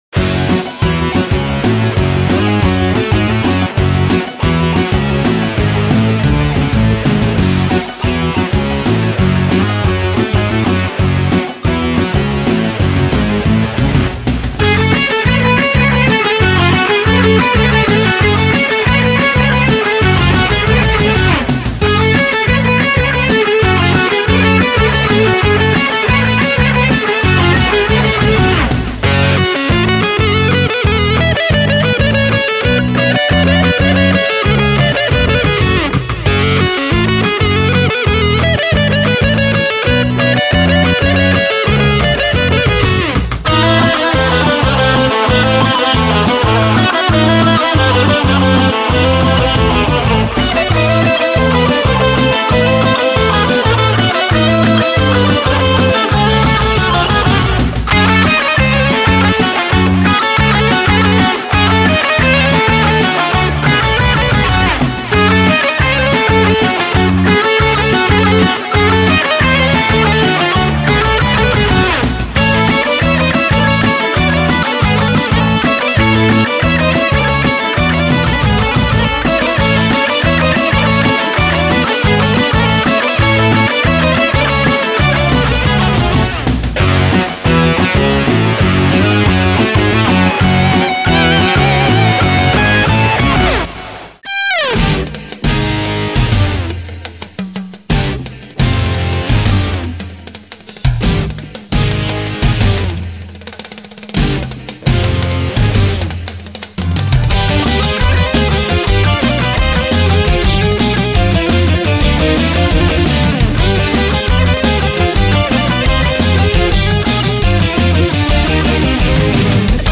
Balkan Fusion, electric guitar
Guitar Rock instrumental